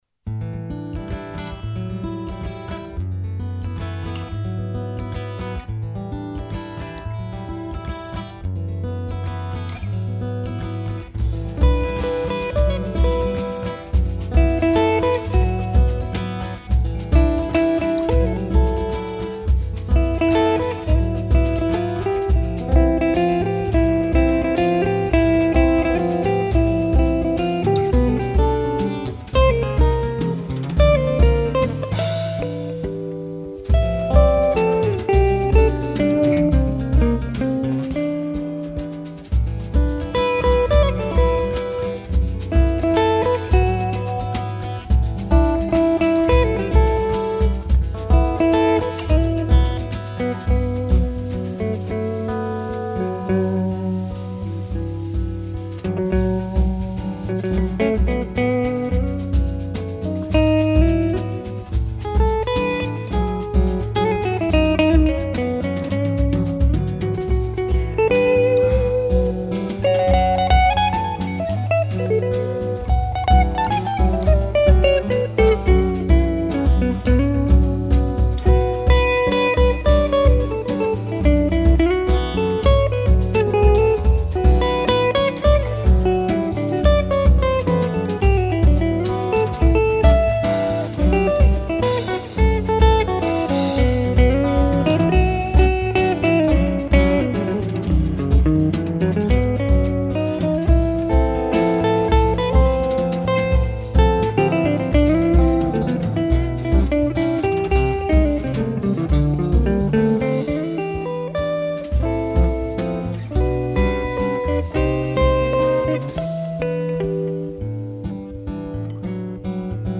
Contemporary.